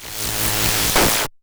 Cwejman S1 - Noise Burt.wav